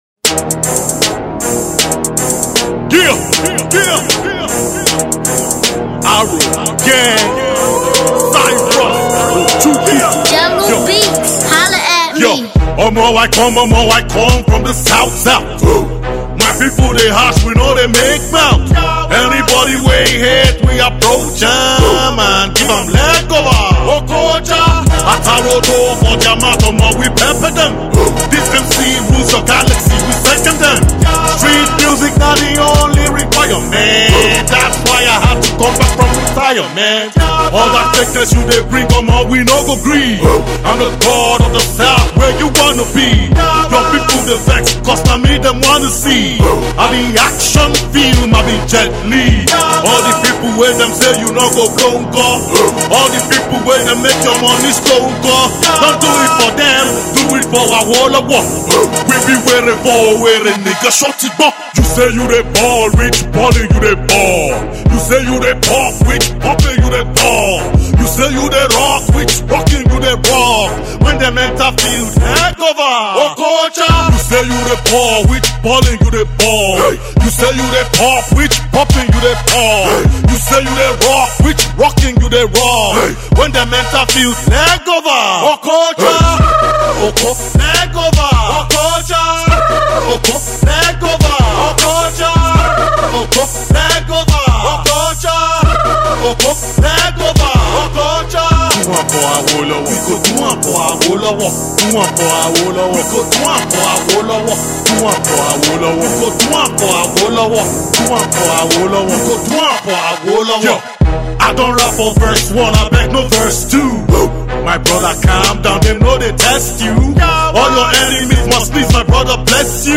This is pure adrenaline